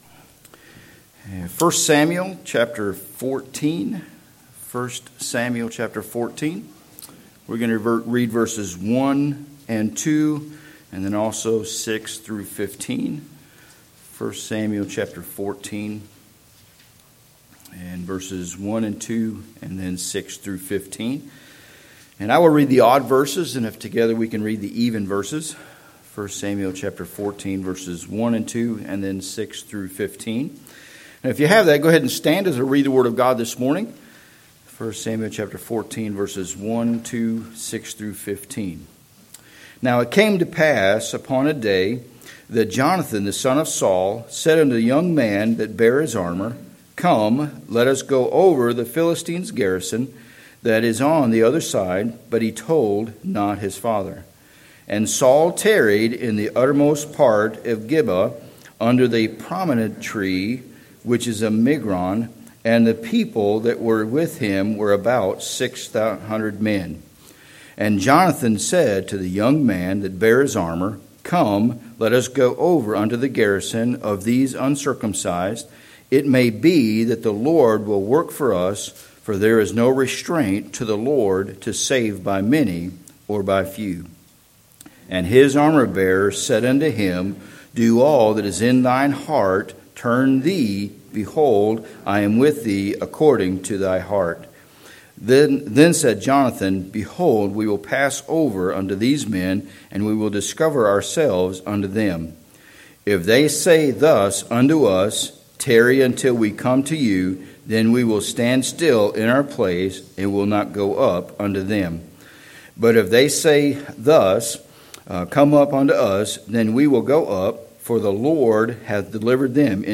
Making A Difference – Lighthouse Baptist Church